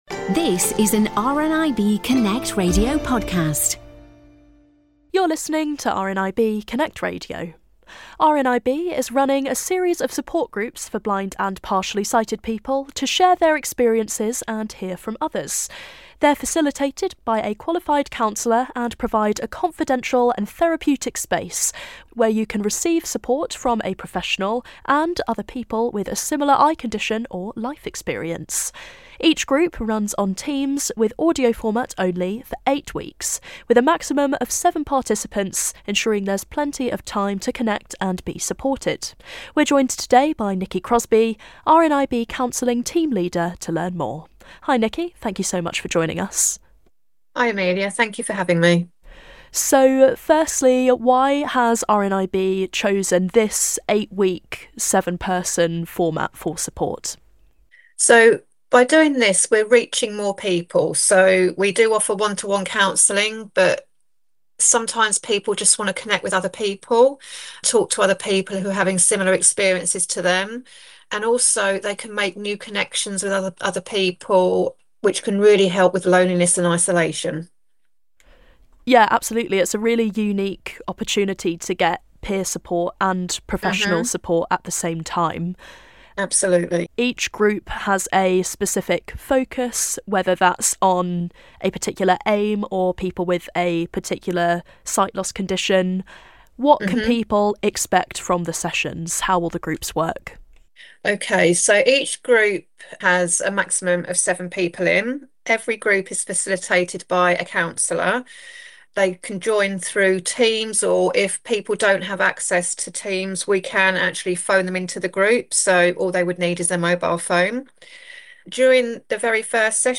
Image shows the RNIB Connect Radio logo.